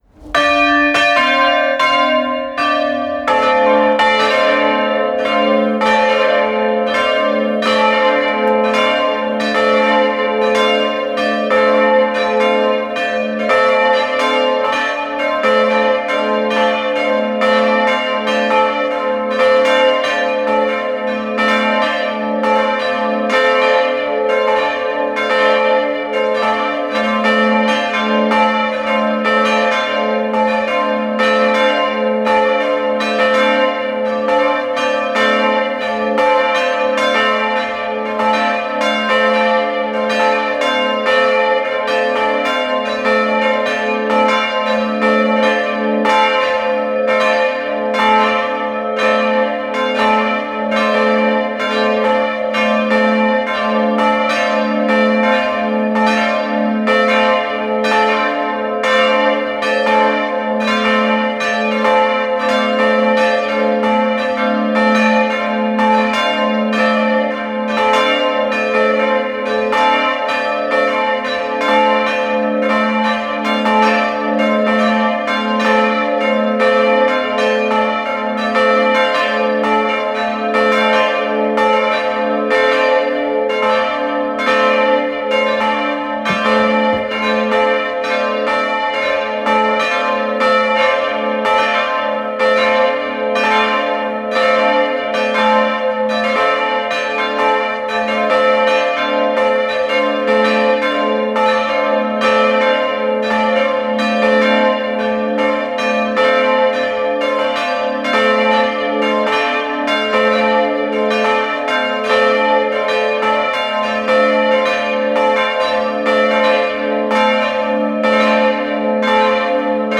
Audio Glocken
Glocken.mp3